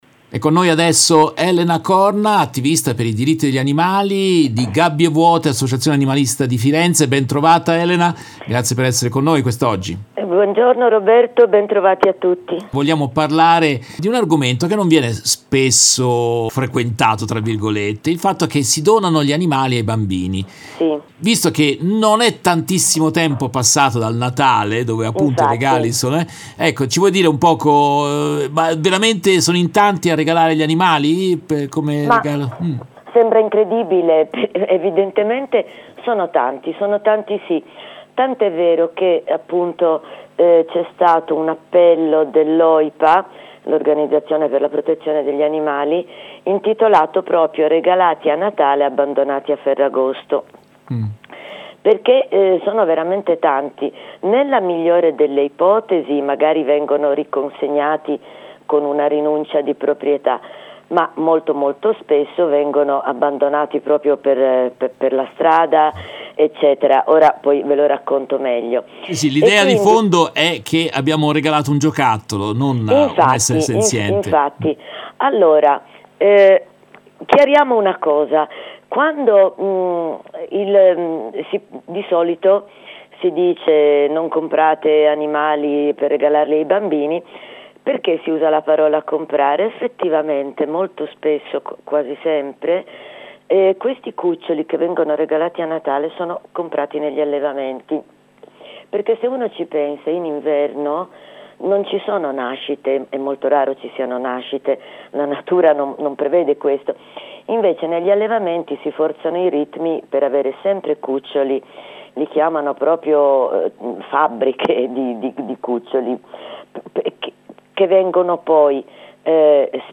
Nel corso della diretta del 19 gennaio 2024